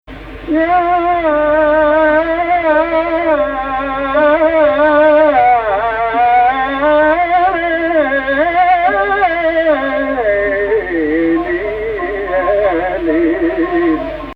Type: 4231 (upper octave) descent => 1